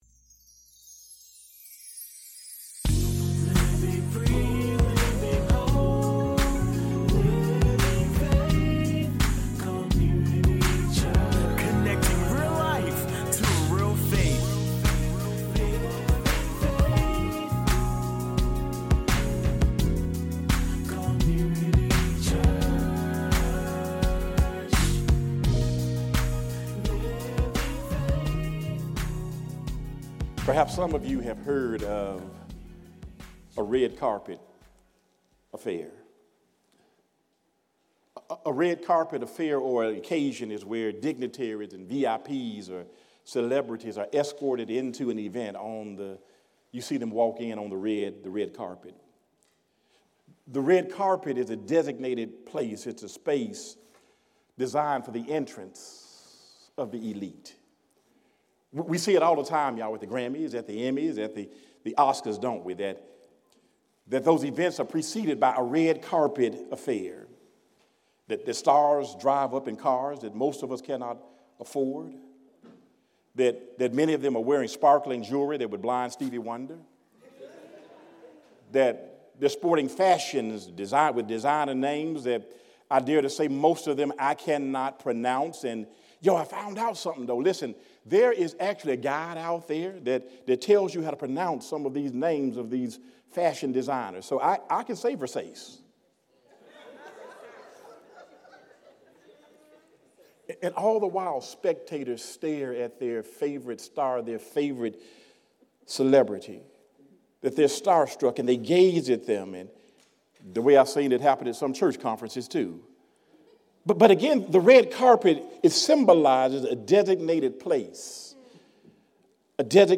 4-13-25 “A Red-Carpet Affair.” - Sermon